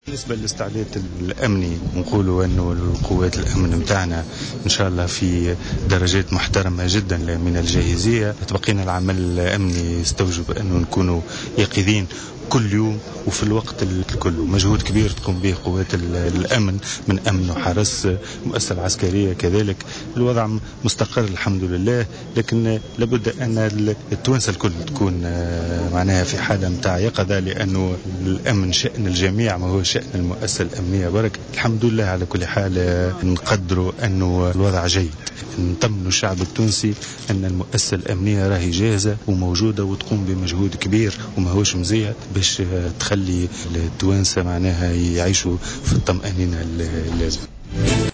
وأضاف في تصريح لمراسل "الجوهرة أف أم" على هامش زيارته ليلة البارحة لمدينة القيروان وإشرافه على الاحتفالات بليلة القدر بجامع عقبة بن نافع، أن الوحدات الأمنية والعسكرية على أهبة الاستعداد والجاهزية للتصدي لأي خطر محتمل.